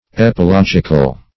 Search Result for " epilogical" : The Collaborative International Dictionary of English v.0.48: Epilogic \Ep`i*log"ic\, Epilogical \Ep`i*log"ic*al\, a. [Gr.
epilogical.mp3